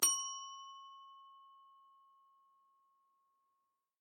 Звуки настольного звонка